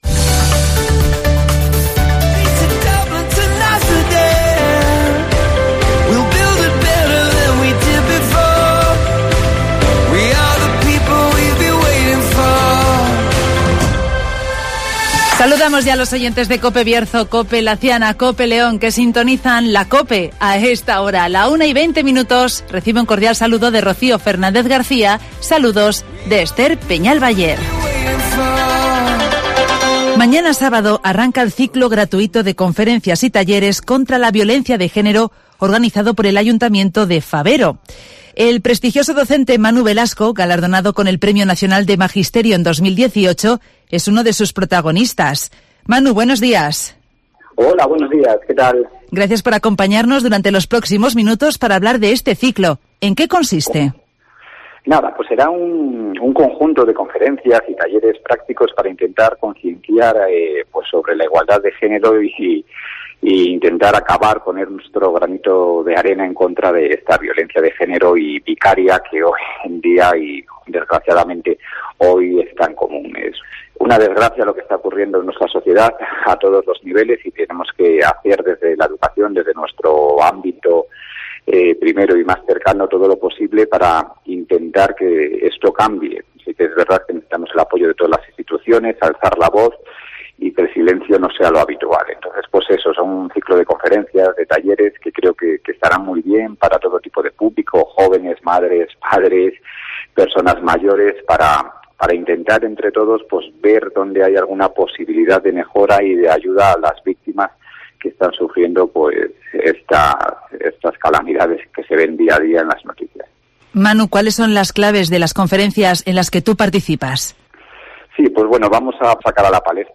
Ciclo gratuito de conferencias y talleres contra la violencia de género en Fabero (Entrevista